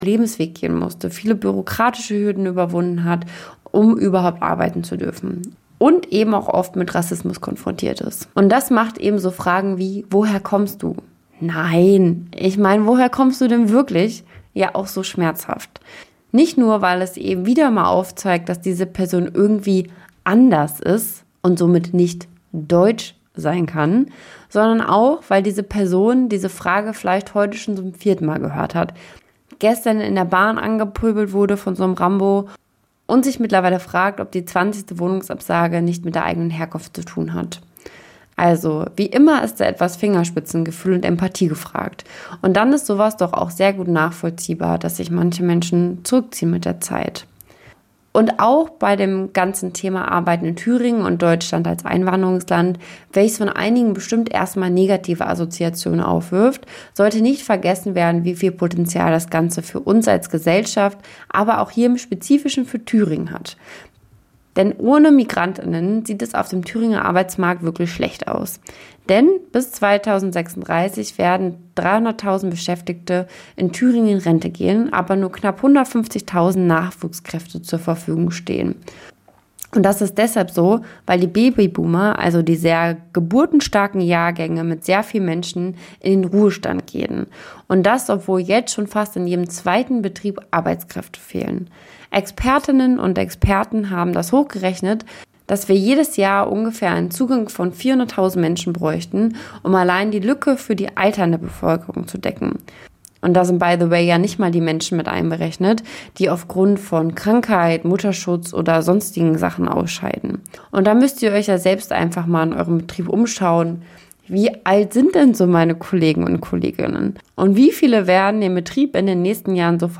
Boogie-Woogie - am Klavier 1 circa 1940
Eine Stunde Jazz Dein Browser kann kein HTML5-Audio.